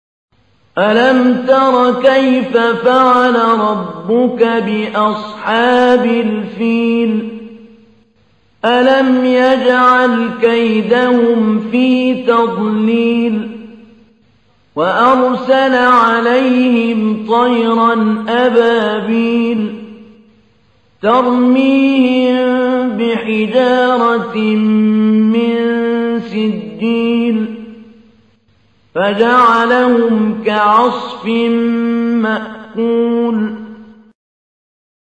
تحميل : 105. سورة الفيل / القارئ محمود علي البنا / القرآن الكريم / موقع يا حسين